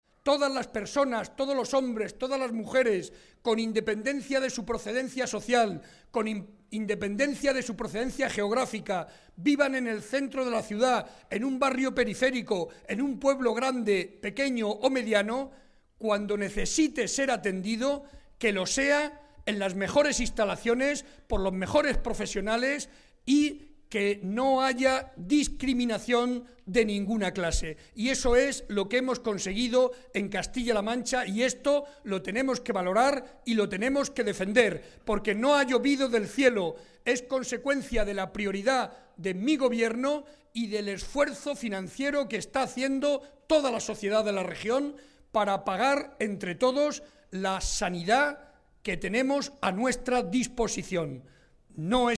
El presidente, que esta tarde estuvo en Fuente el Fresno (Ciudad Real), resaltó el gran esfuerzo del Gobierno regional para mejorar la calidad de vida de los vecinos de Fuente el Fresno y de Castilla-La Mancha.
01-BARREDA-FUENTELFRESNO1.mp3